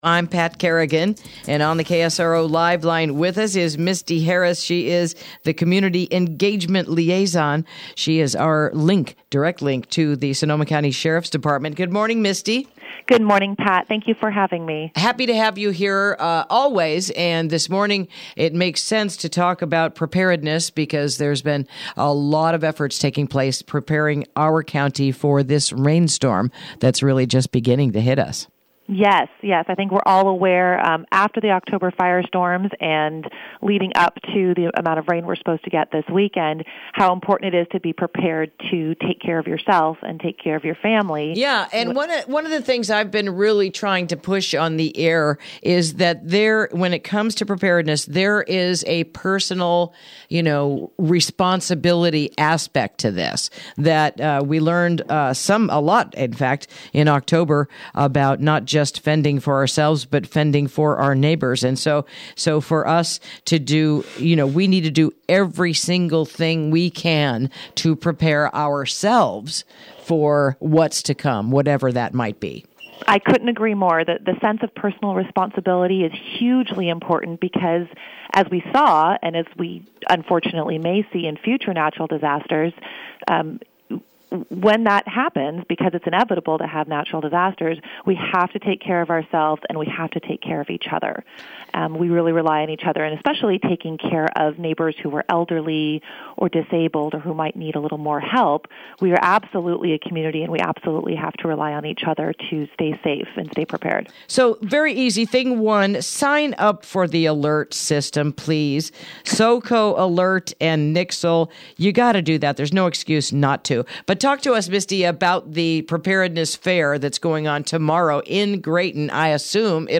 Interview: You’re Invited to This Saturday’s Graton Fire Department Safety Fair